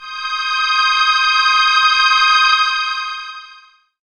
37j02pad1-c.wav